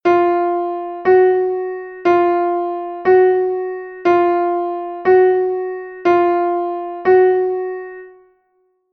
sost Chámase sostido. Fai ascender 1/2 ton o son da nota.